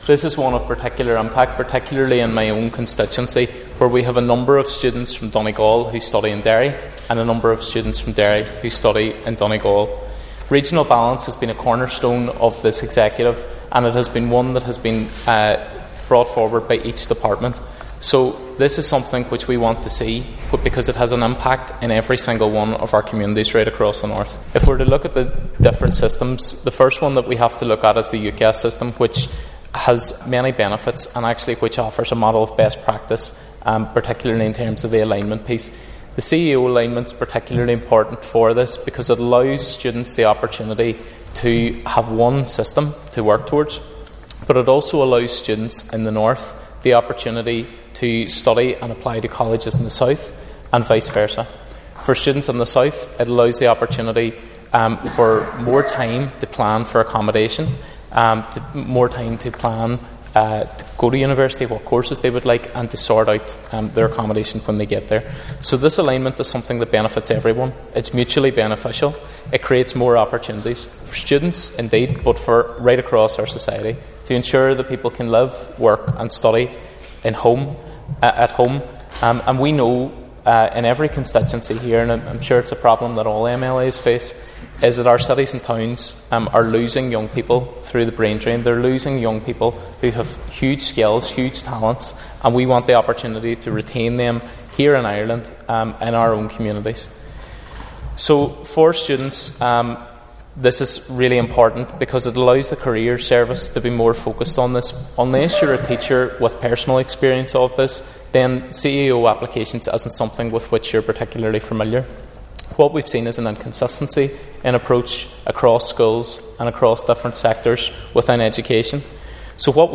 You can hear Mr Delargey’s full speech here –